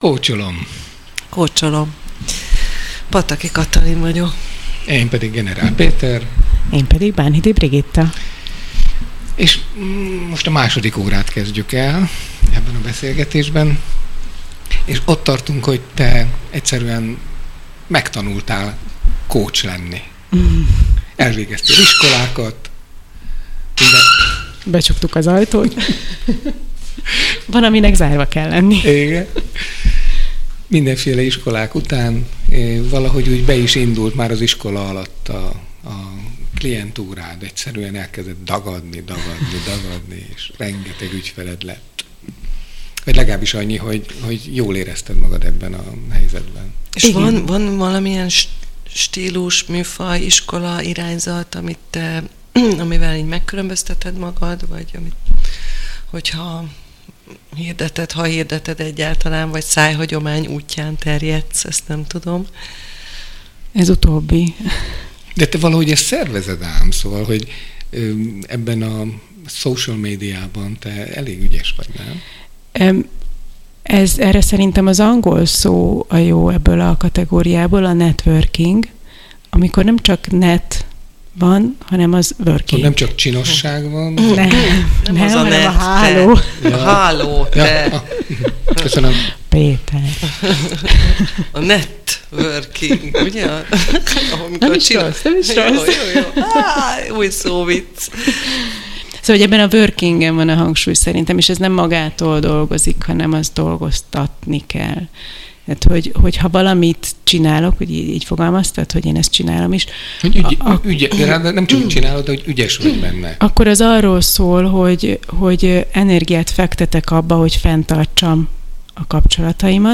Rendkívül kellemes 2 órát töltöttem el a Rádió Bézs stúdiójában